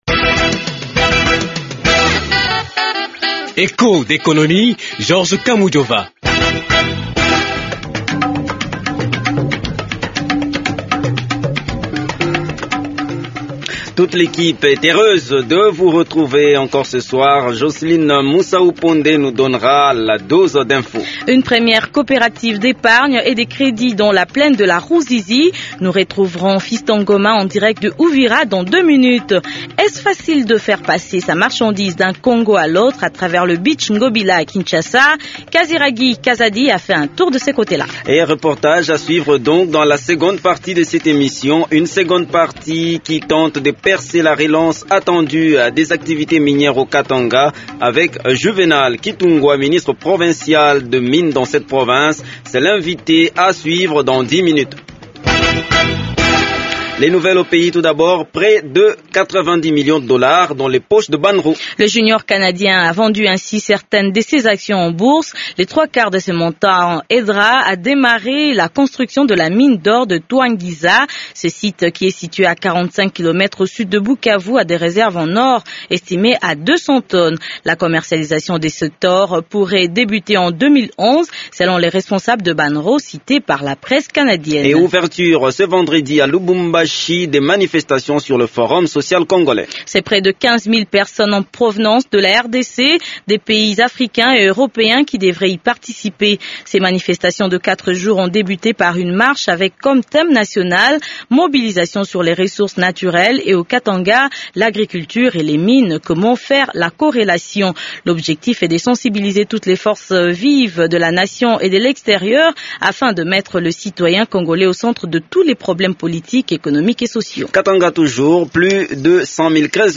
Le ministre katangais des mines est l’invité de cette émission. Avec lui, nous sondons la relance attendue des activités minières dans le sud de la RDC. Echos d’Economie aborde aussi ces multiples tracasseries fiscales auxquelles sont soumises les trafiquants entre les deux Congo.